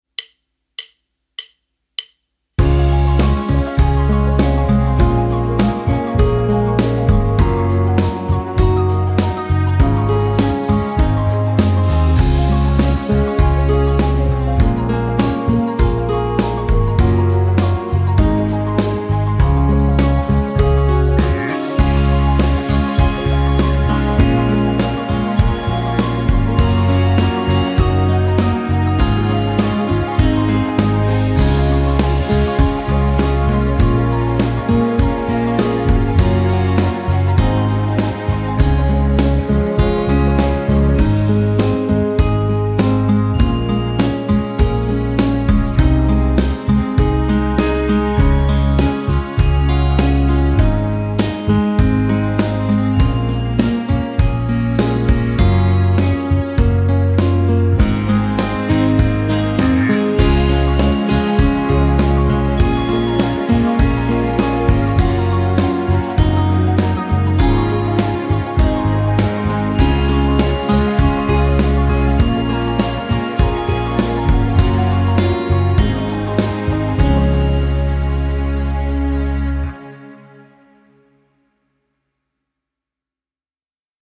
Besetzung: Instrumentalnoten für Posaune